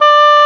WND OBOE D04.wav